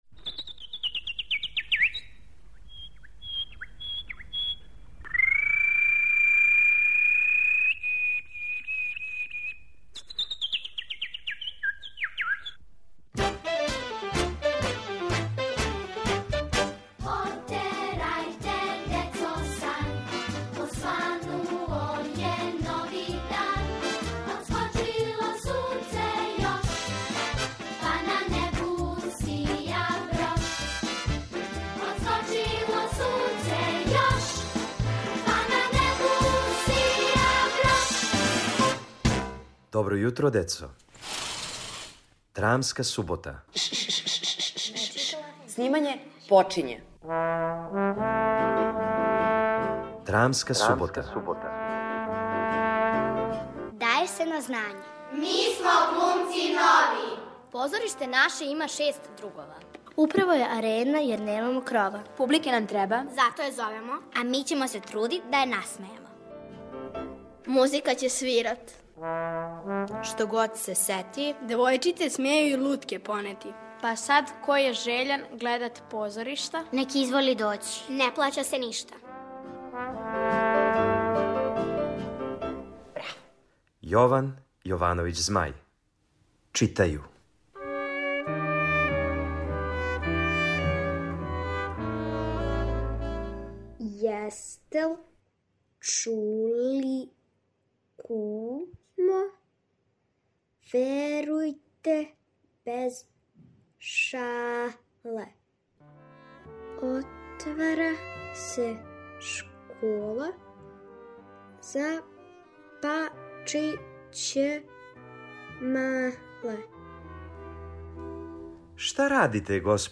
У "Драмској суботи" слушате мале драмске форме Јована Јовановића Змаја. Данашња је настала од песме "Читају".